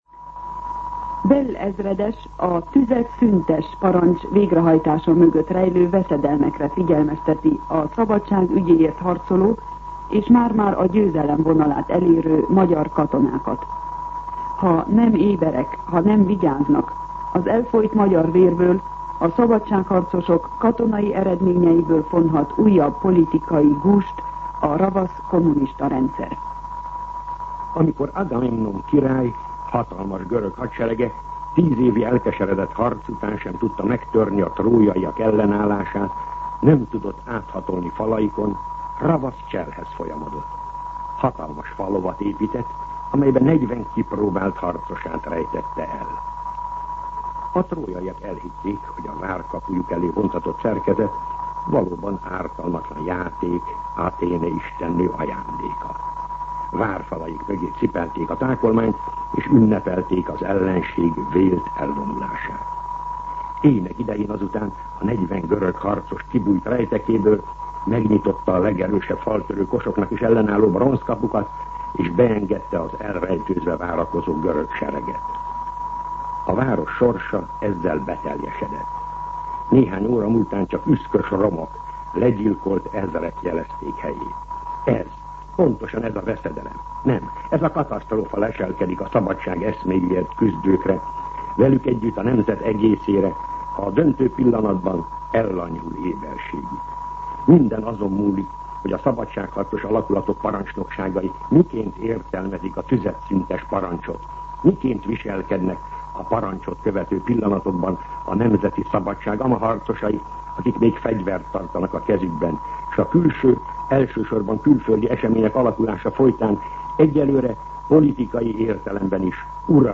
Katonapolitikai kommentár